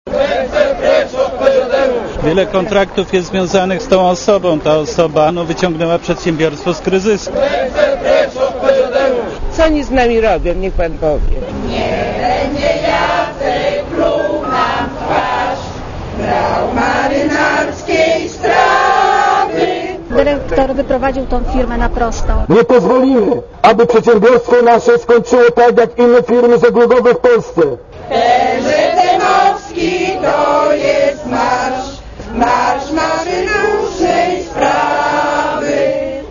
* Posłuchaj protestujących pracowników*
szczecin-pzm-protest.mp3